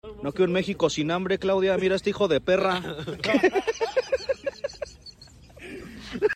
mexico sin hambre Meme Sound Effect
Category: Games Soundboard